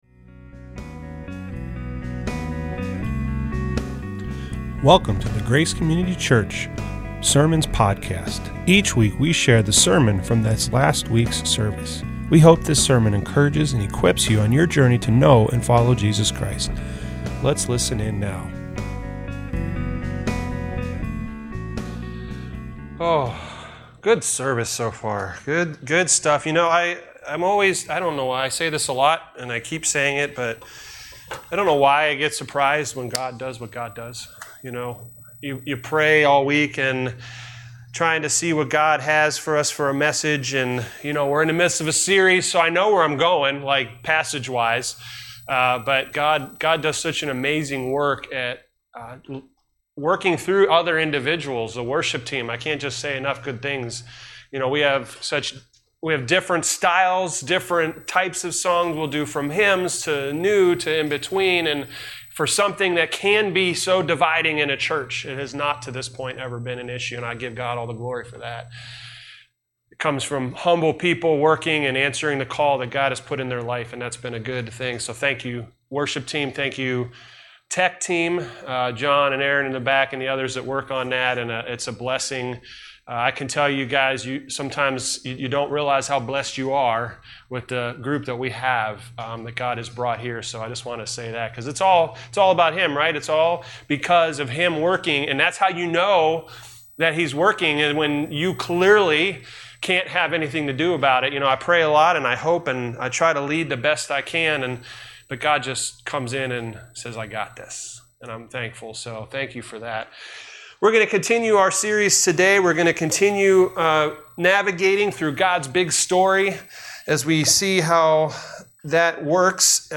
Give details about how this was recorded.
Sunday, July 7th, 2024 | Grace Community Church of Alliance